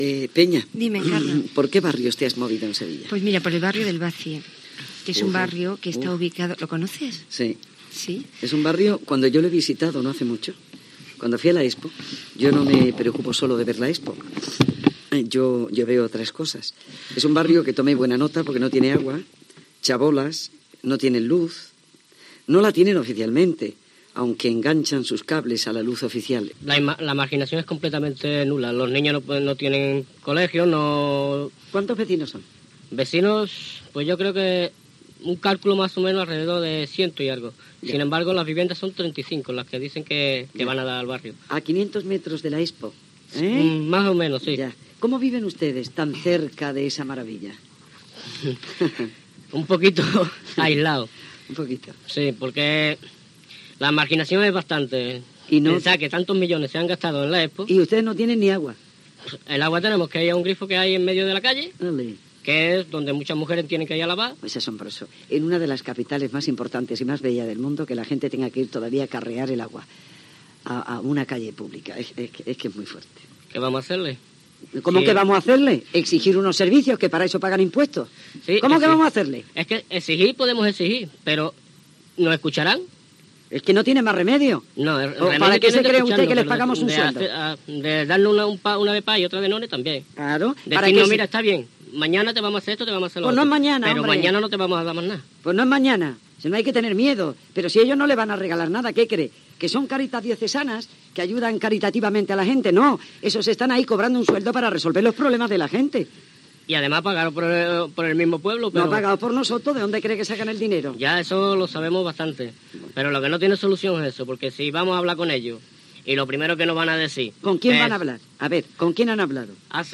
Denúncia de la situació del barri de barraques El Vacie, situat a la zona nord de Sevilla, junt al Cementerio de San Fernando. Entrevista a un dels veïns
Info-entreteniment